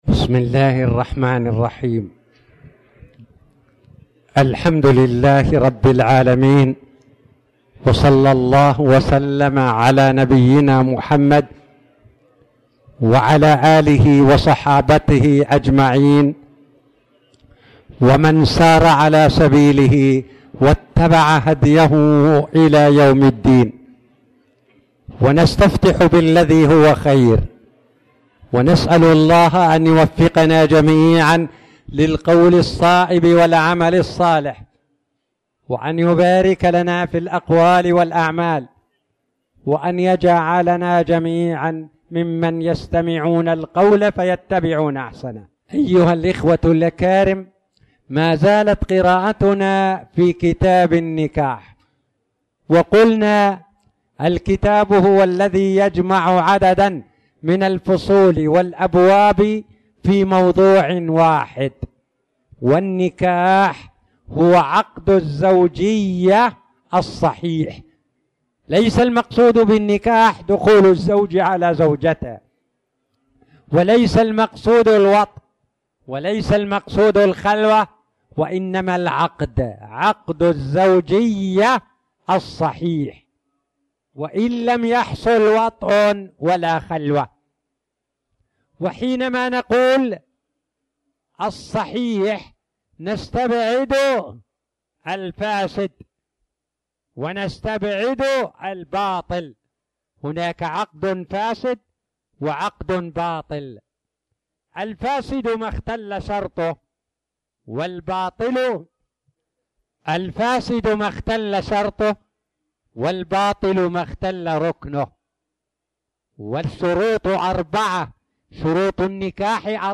تاريخ النشر ١ جمادى الأولى ١٤٣٨ هـ المكان: المسجد الحرام الشيخ